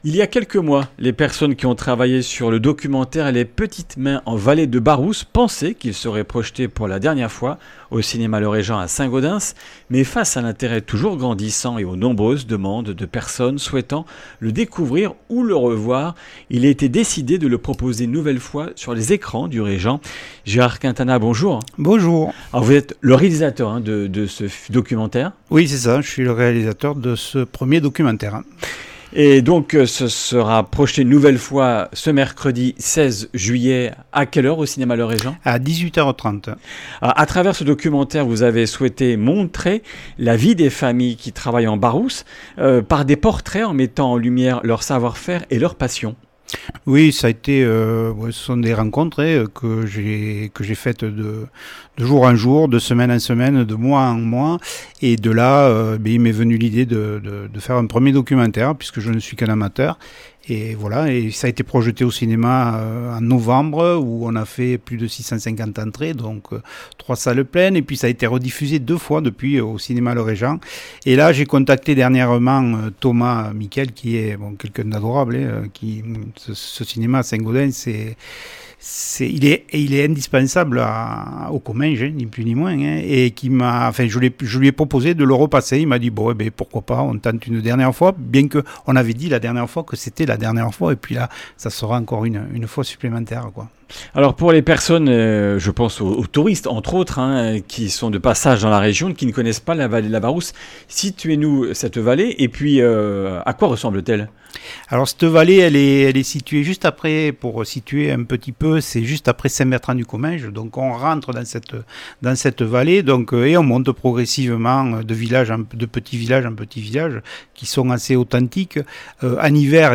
Accueil \ Emissions \ Information \ Locale \ Comminges Interviews \ Comminges Interviews du 15 juil.